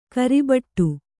♪ karibaṭṭu